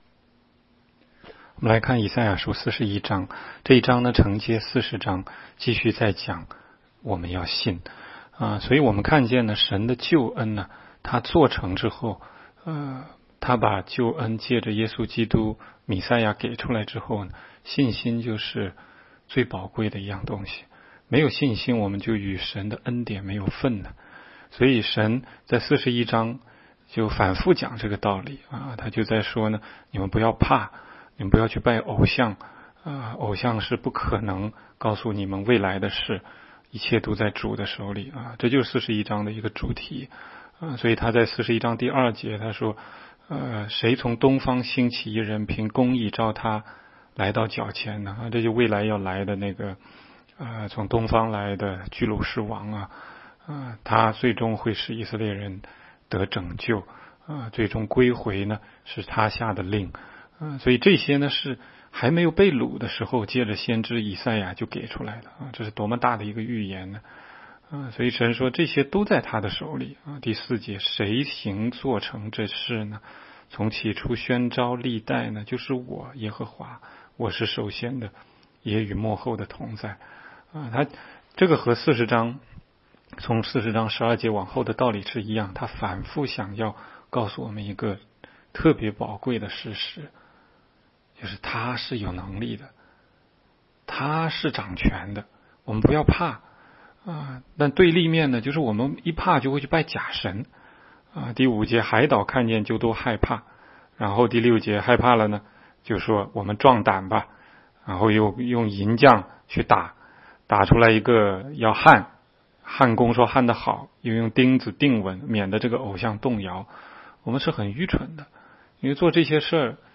16街讲道录音 - 每日读经 -《以赛亚书》41章